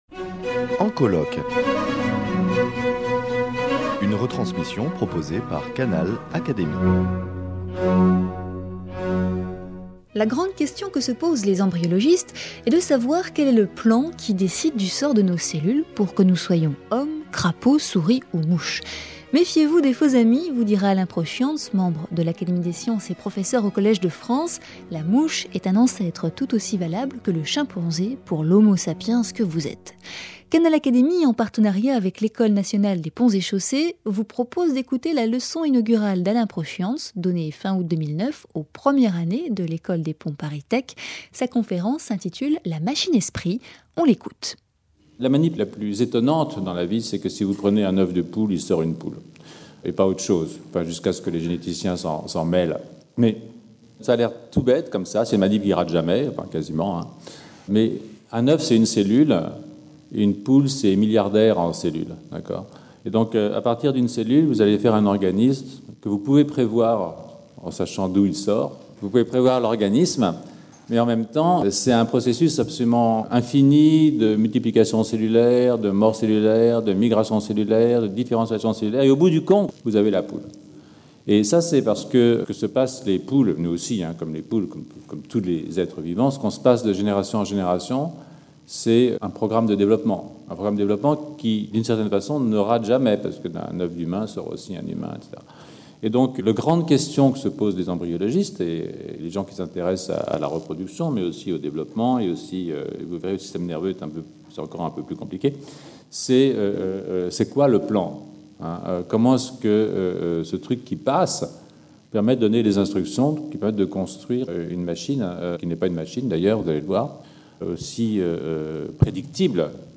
Écoutez la leçon inaugurale d’Alain Prochiantz donnée fin août 2009 à l’ENPC : une intervention, passionnante et accessible à tous.